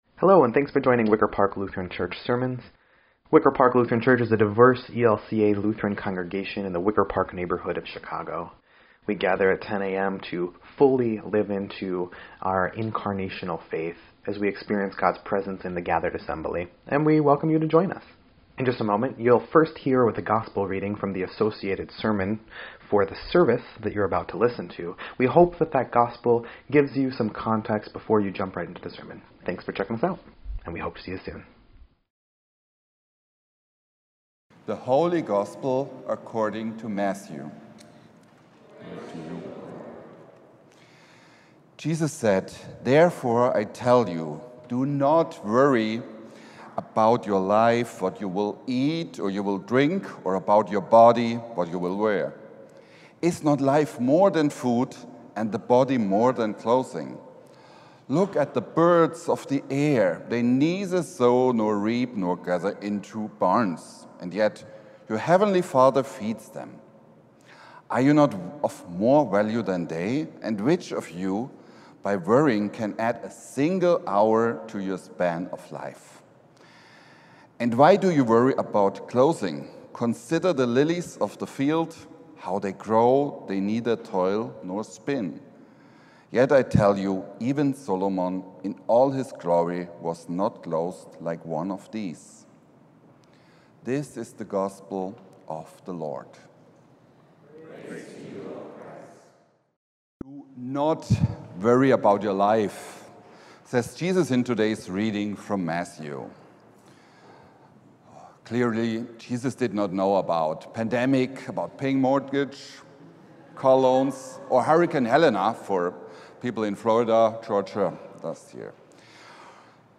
10.6.24-Sermon_EDIT.mp3